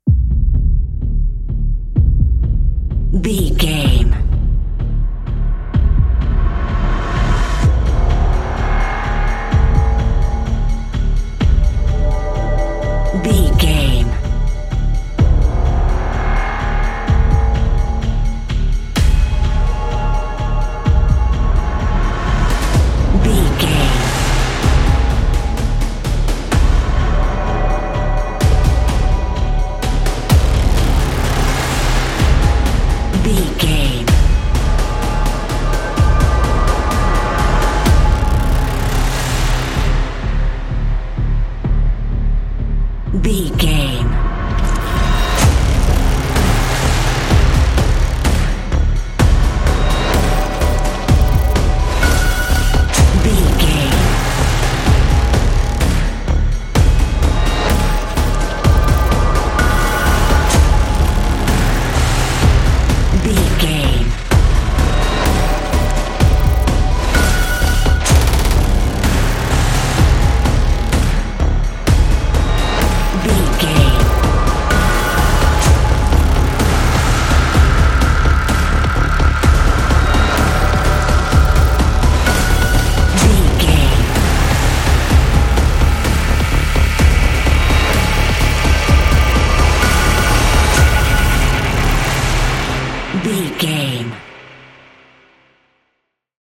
Ionian/Major
aggressive
dark
eerie
intense
menacing
sinister